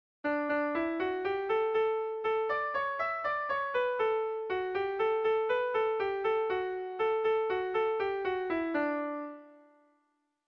Erromantzea
Ligi < Ligi-Atherei < Basabürüa < Zuberoa < Euskal Herria
ABD